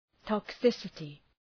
Προφορά
{tɒk’sısətı}